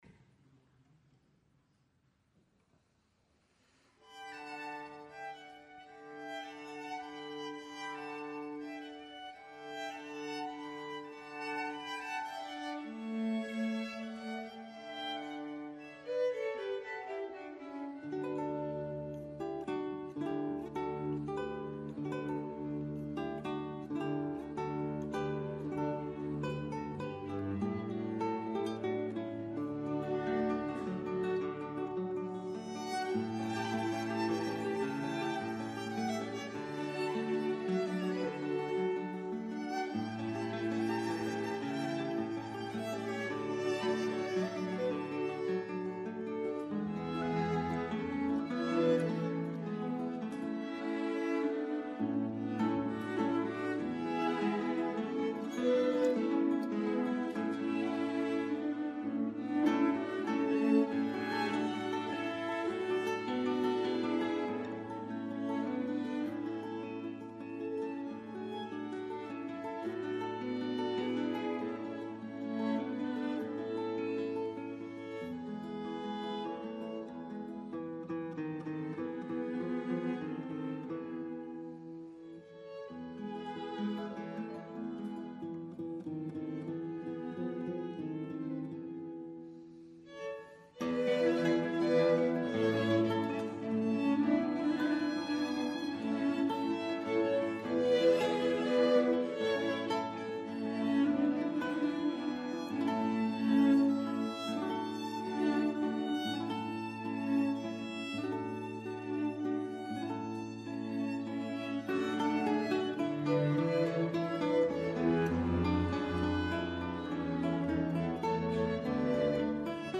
No volia deixar passar l’ocasió de posar al vostre abast aquest joiell de perles que s’ofereix en aquest concert de música de cambra.
quintet per a guitarra i quartet de corda
quintet.mp3